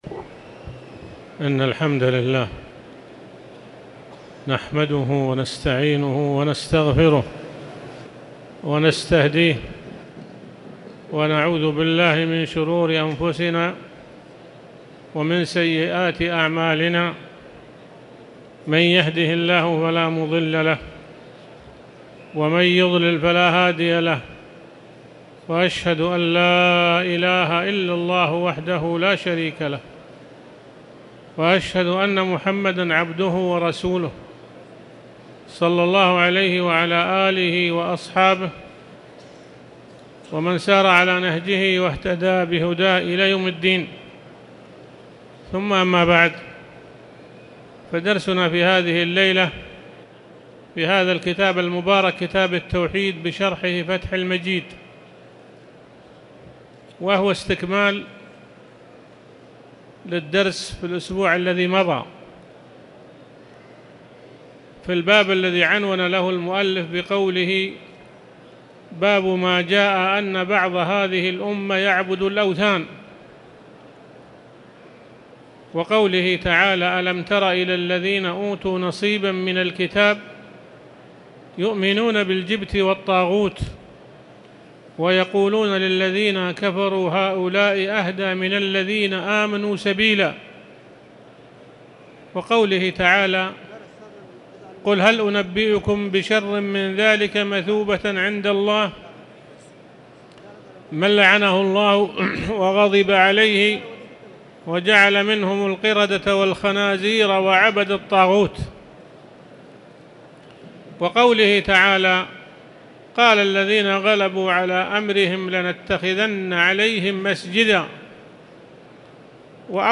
تاريخ النشر ٢٩ جمادى الآخرة ١٤٣٨ هـ المكان: المسجد الحرام الشيخ